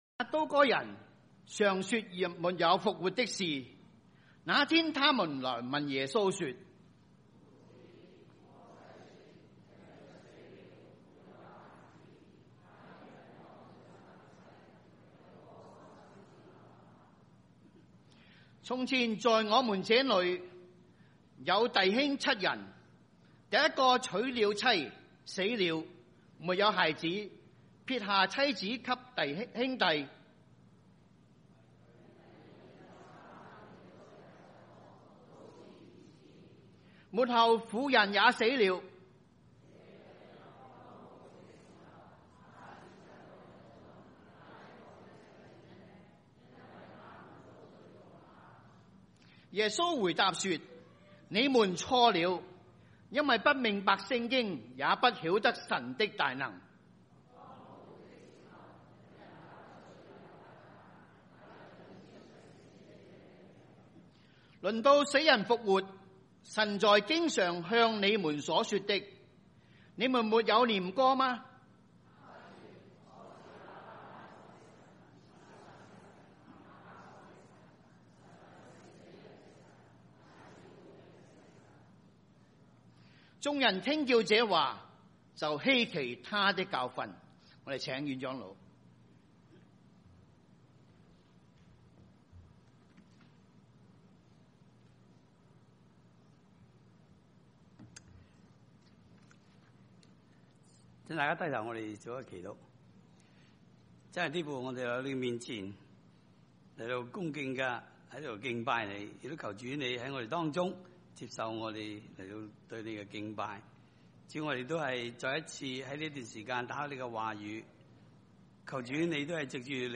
華埠粵語二堂